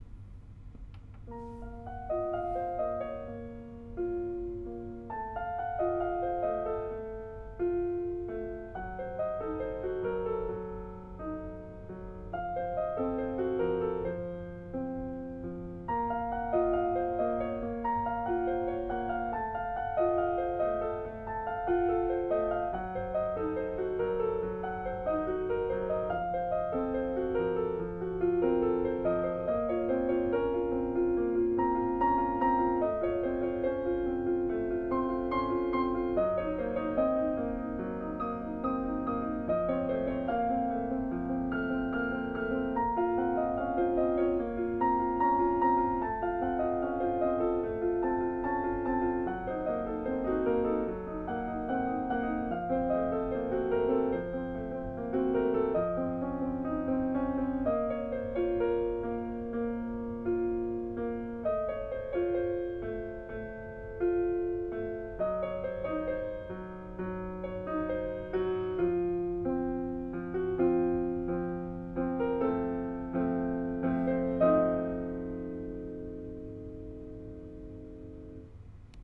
Its sound is much richer and more resonant than my old piano, and there are more choices and features, which will be fun to explore in the coming days.
I thought of the tinkling sound of angel voices, their flapping wings, more and more joining the chorus, imploring God to help us.